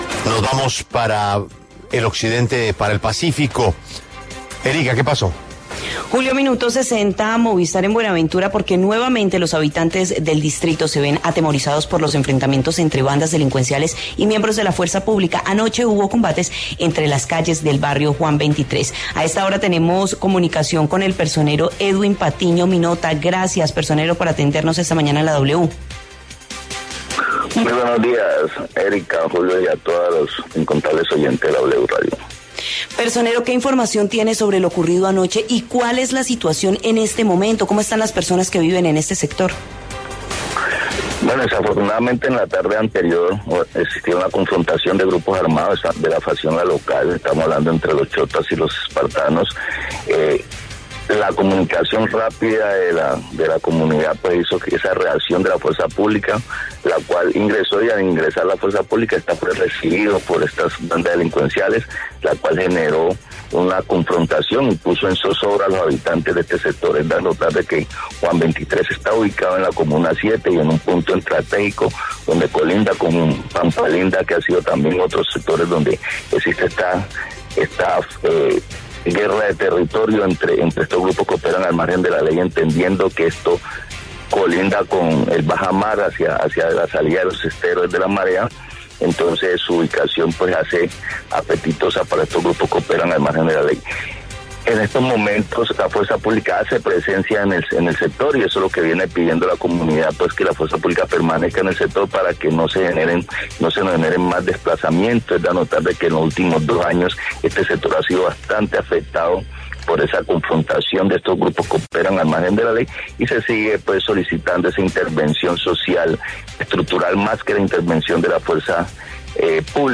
Edwin Janes Patiño Minotta, personero distrital, entregó detalles en La W sobre la balacera que se presentó en el barrio Juan XXIII de Buenaventura.